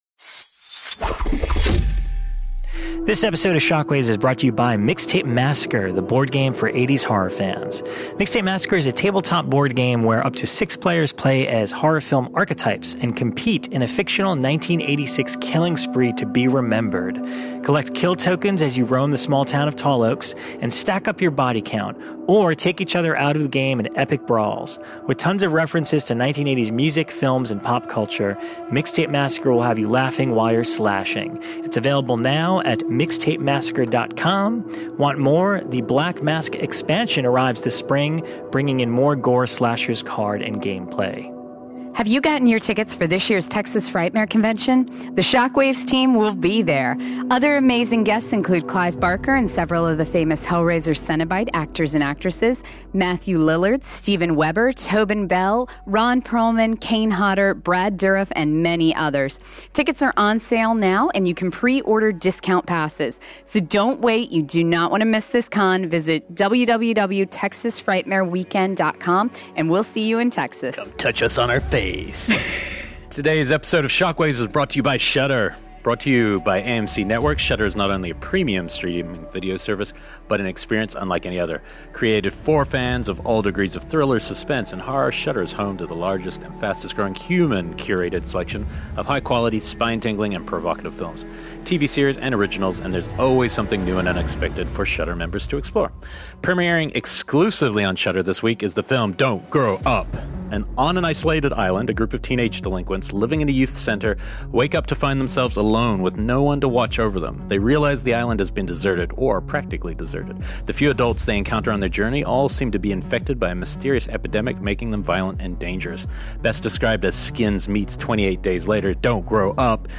Then the gang are joined by friend and colleague Mick Garris!